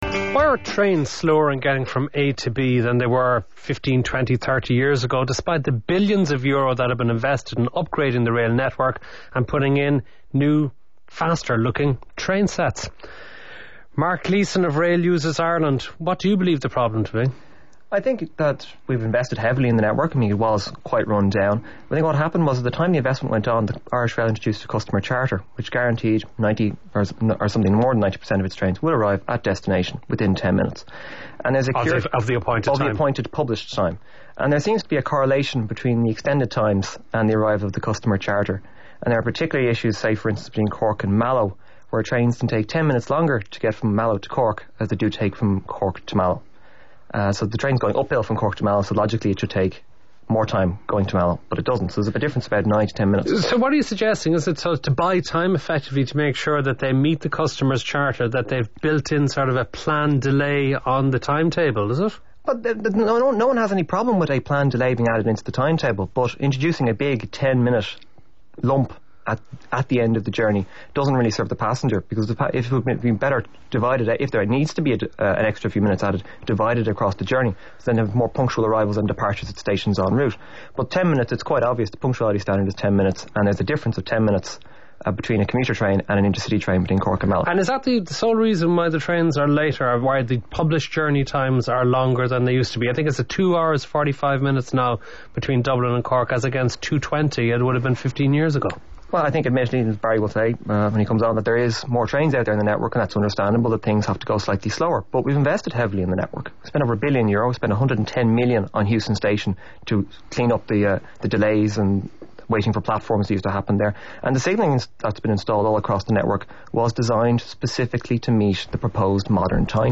Discussion of the news featured in the Irish Examiner which showed train times are no better if not longer on some routes than in the 1970's and 1990's. Last Word Today FM with Matt Copper in the chair